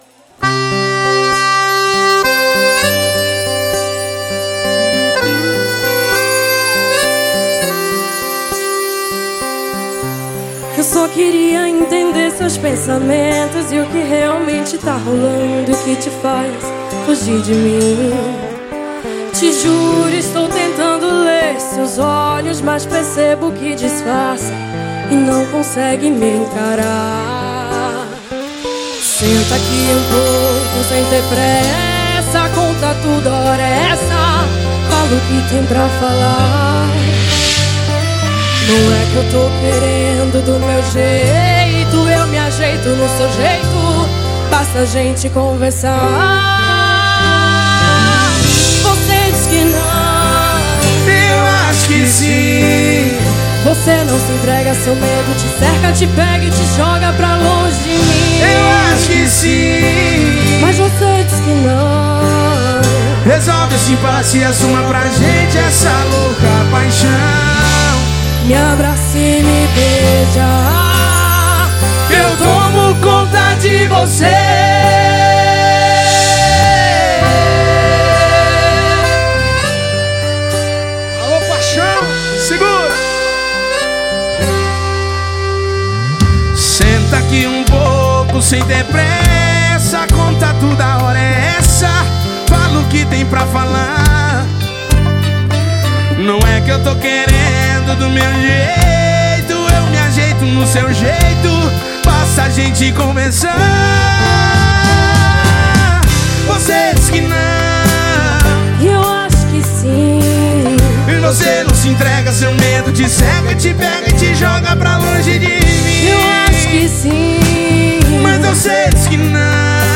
Cover.